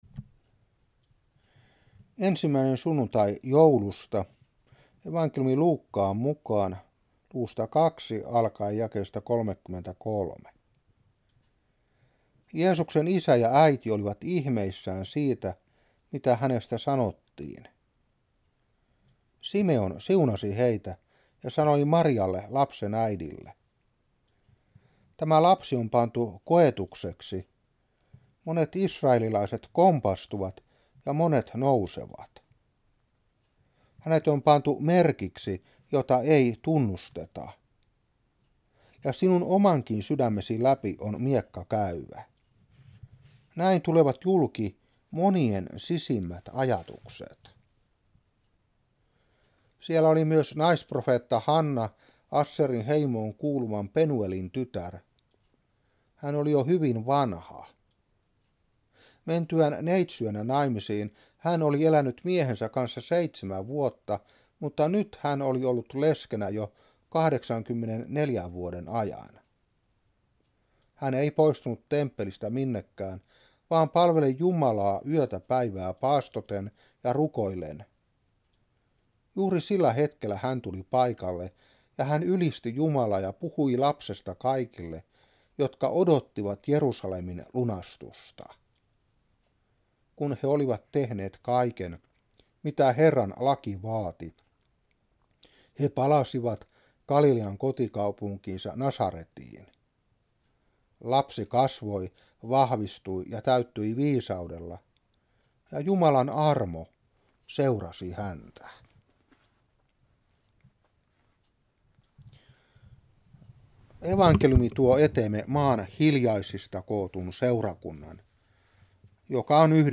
Saarna 1992-12.